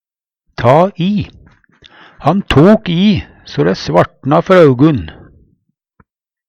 DIALEKTORD PÅ NORMERT NORSK ta i ta i, bruke mykje kraft Eksempel på bruk Han tok i so dæ svartna før augun.